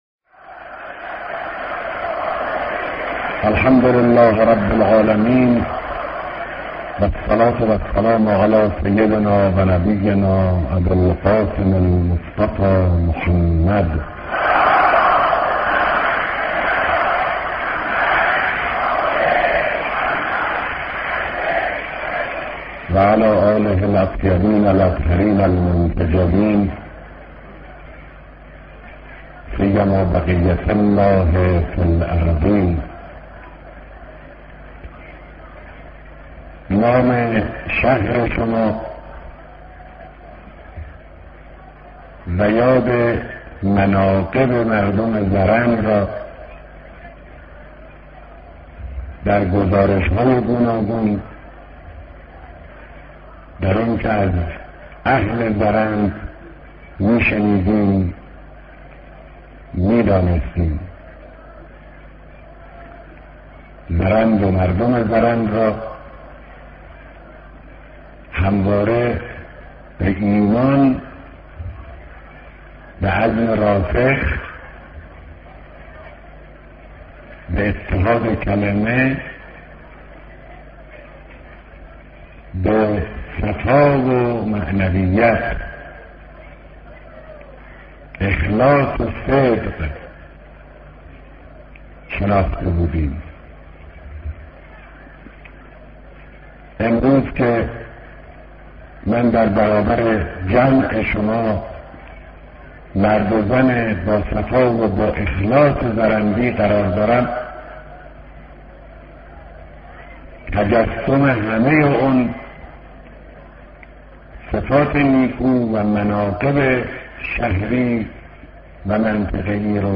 بيانات رهبر معظم انقلاب اسلامى در اجتماع بزرگ مردم شهرستان زرند